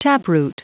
Prononciation du mot taproot en anglais (fichier audio)
Prononciation du mot : taproot